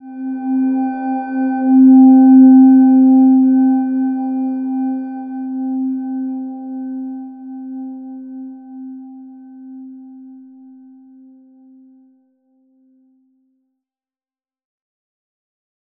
Silver-Gem-C4-f.wav